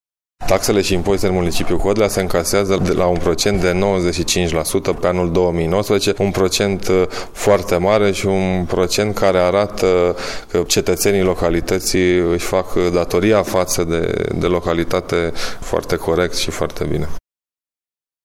Viceprimarul cu atribuţii de primar la Codlea, Mihai Câmpeanu: